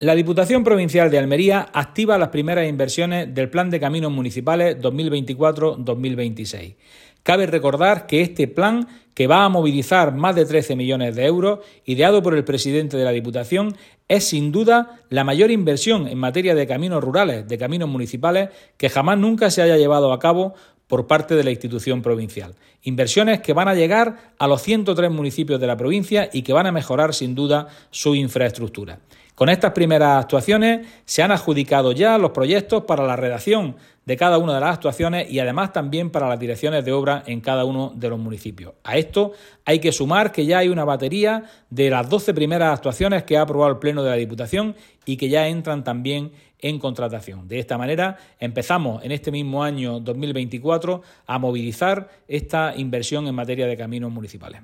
diputado_plan_caminos_-1.mp3